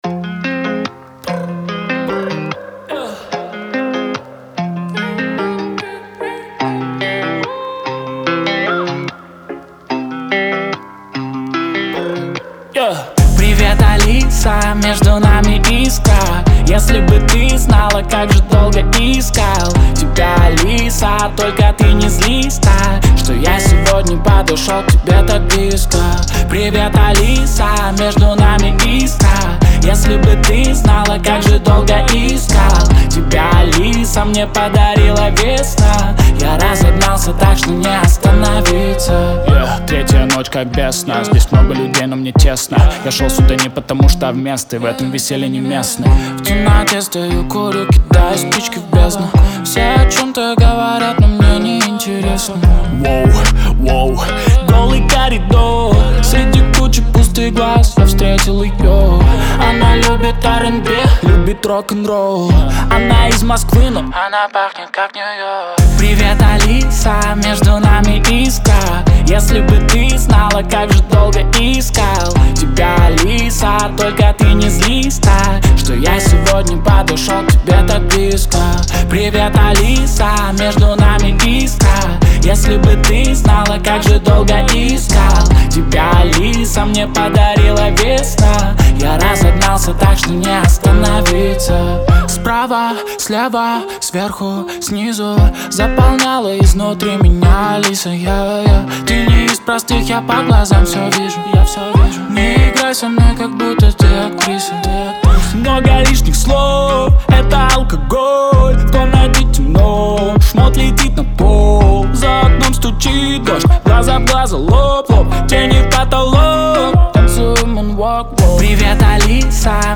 это яркая и запоминающаяся песня в жанре поп-рок